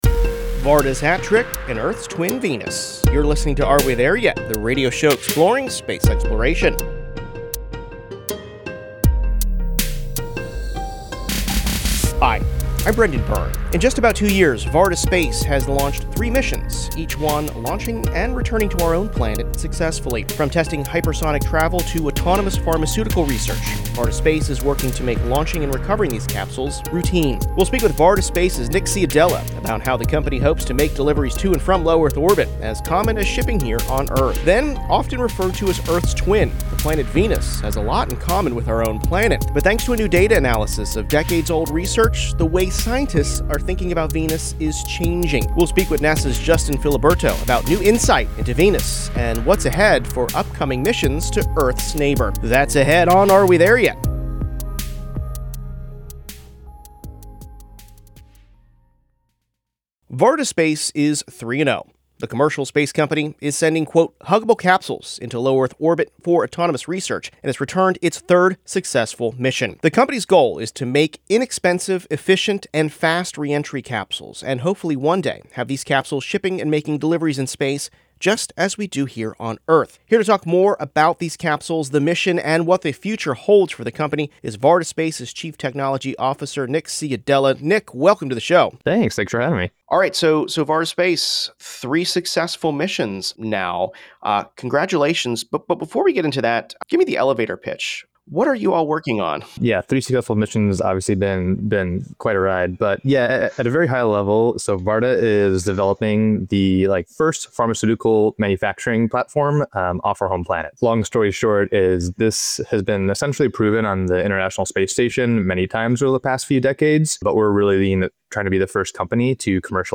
Listen to interviews with astronauts, engineers and visionaries as humanity takes its next giant leap exploring our universe.